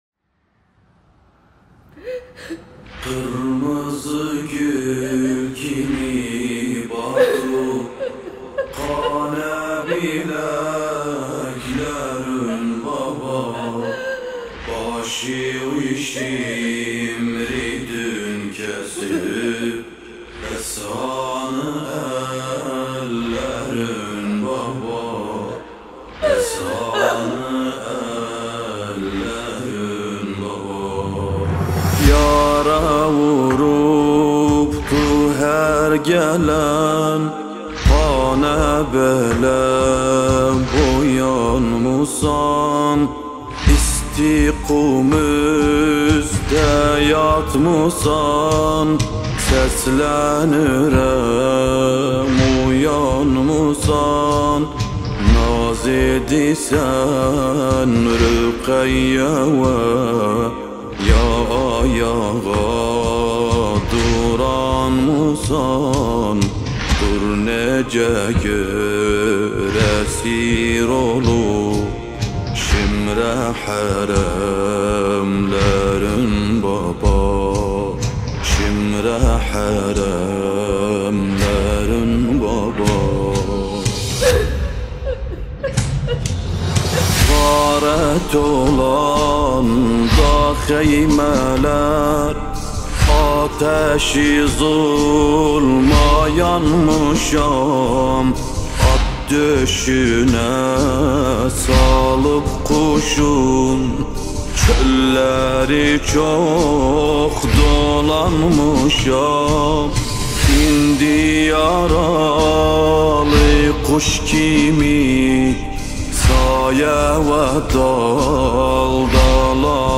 گلچین نوحه های ترکی عراقی با صدای دلنشین